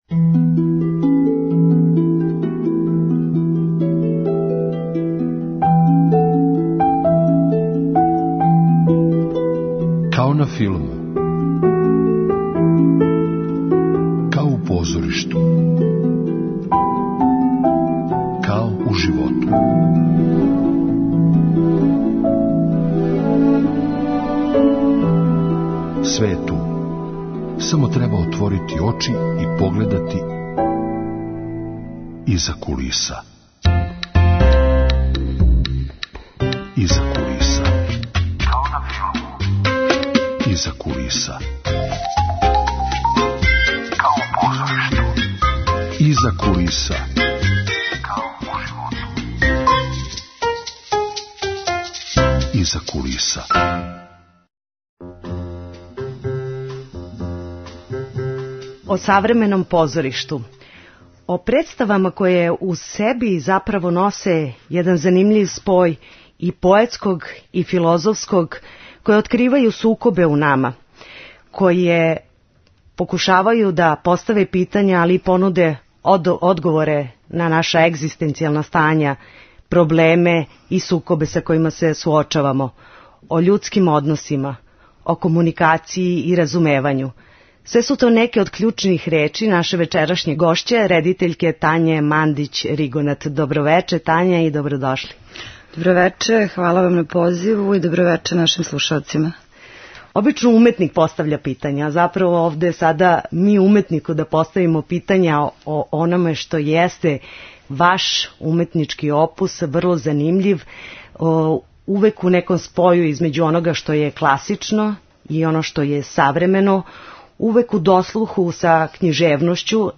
Емисија о филму и позоришту.